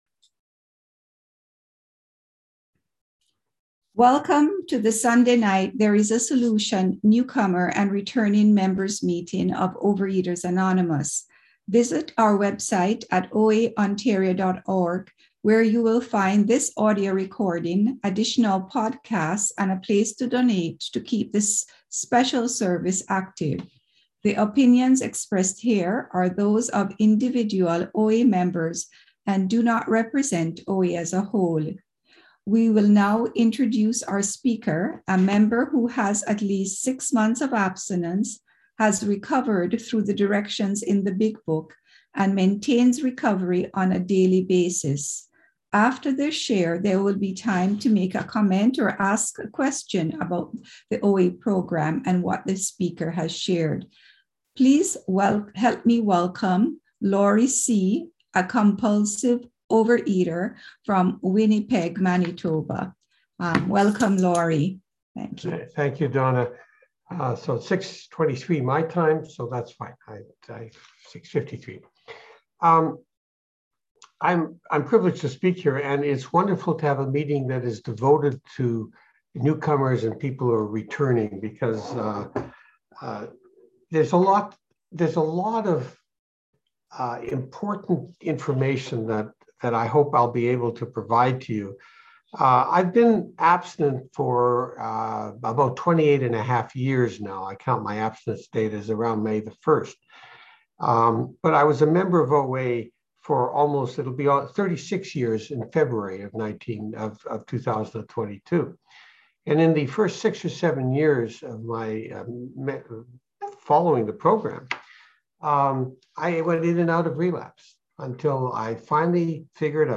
Overeaters Anonymous Central Ontario Intergroup Speaker Files OA Newcomer Meeting